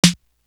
Lose Ya Life Snare.wav